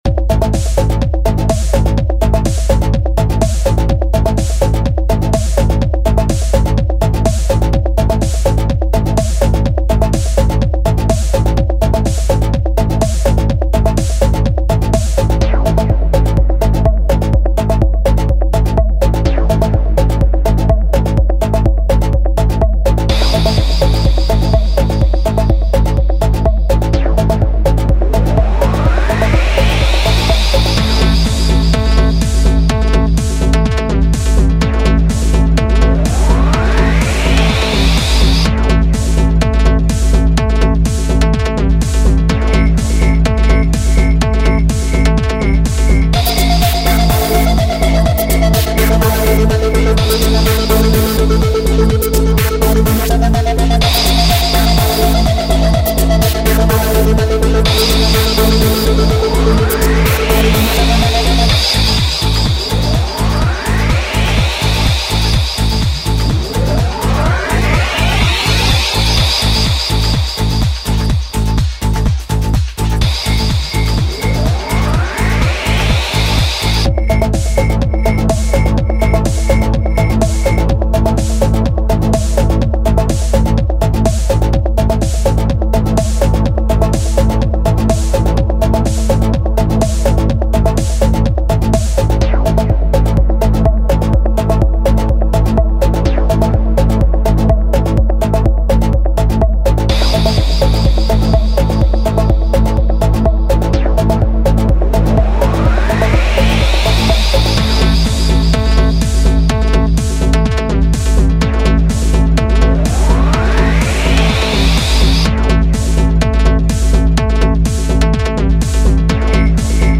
Home > Music > Ambient > Running > Chasing > Restless